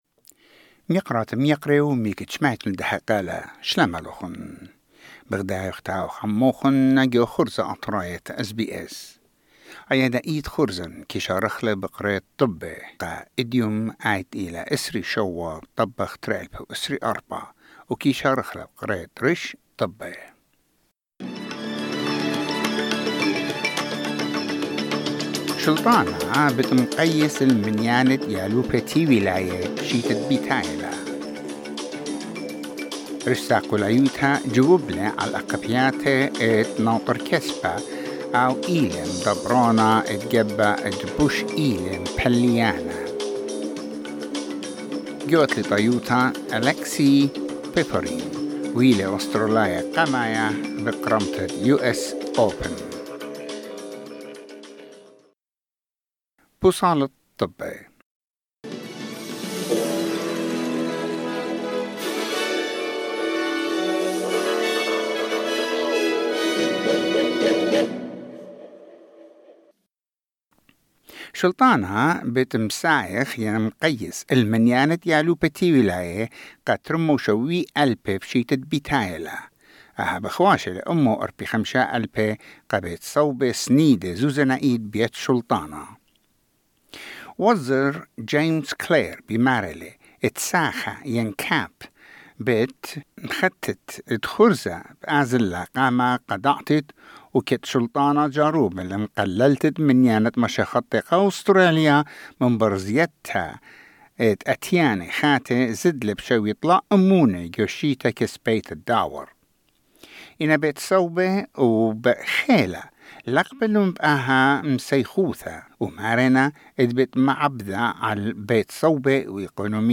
SBS Assyrian news bulletin: 27 August 2024